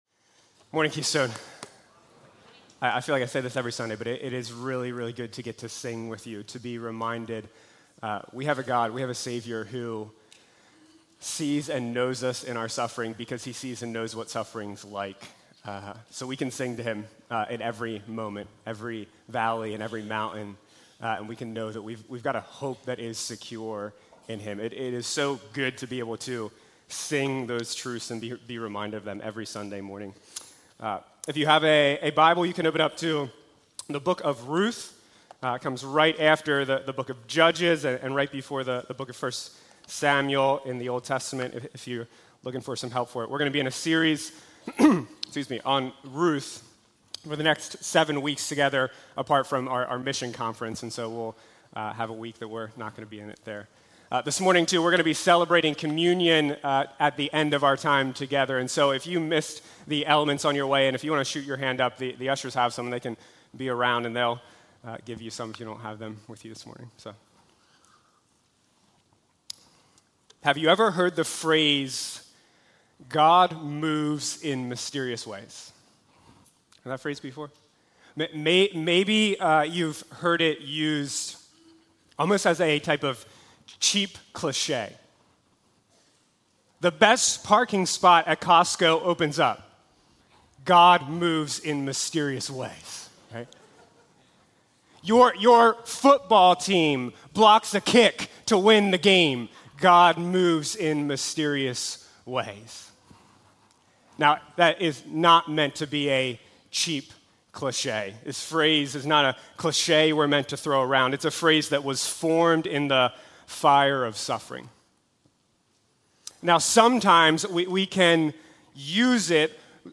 Keystone Church | Paradise Sermons Podcast - Ruth // The Bitterness of Life | Free Listening on Podbean App